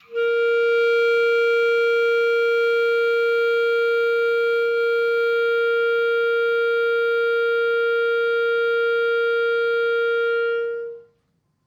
DCClar_susLong_A#3_v3_rr1_sum.wav